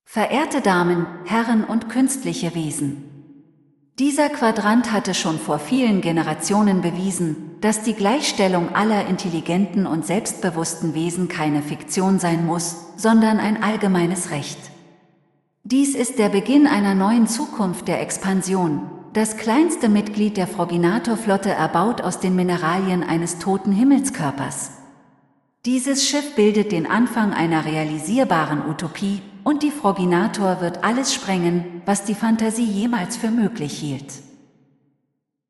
Ich habe verschiede Sprachsynthesen für die Vertonung getestet.
Test - MS-Edge:
MS_Edge_Voice.mp3